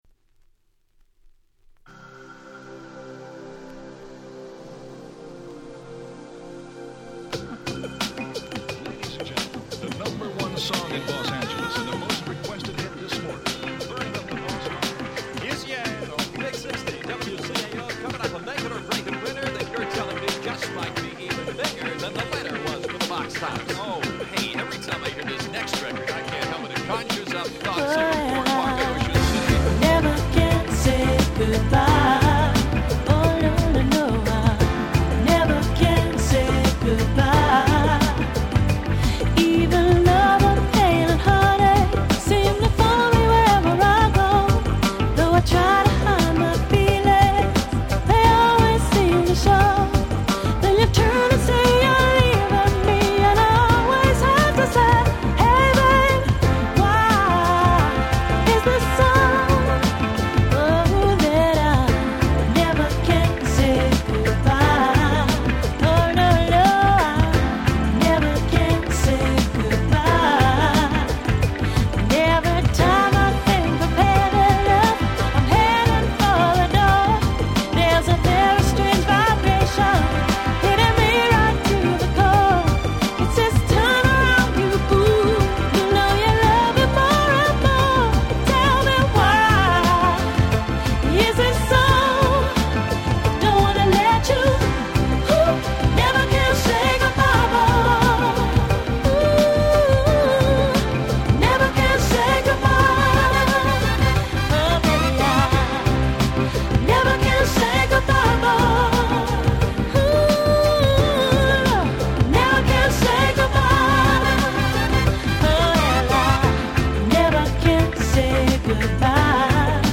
97' Nice UK R&B !!
原曲の良さを損なう事なく、土臭さを残しつつも踊れる非常に良い出来です！